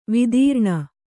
♪ vidīrṇa